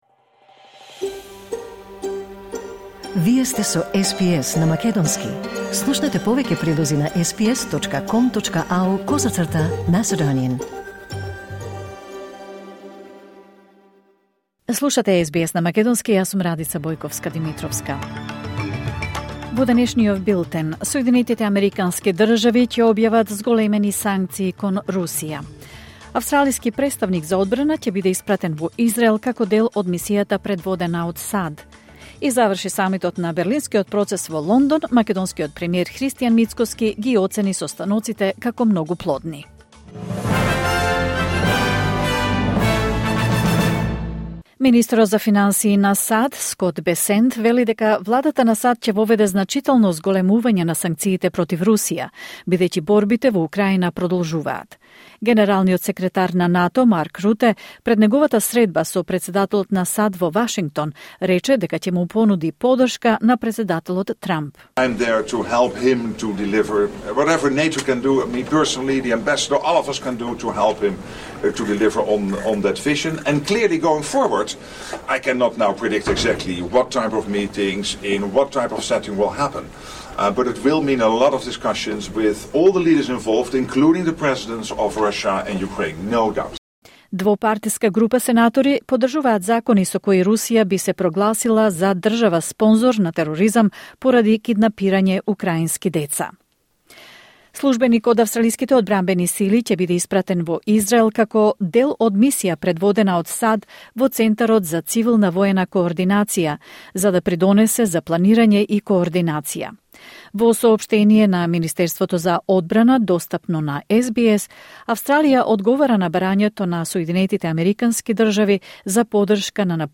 Вести на СБС на македонски 23 октомври 2025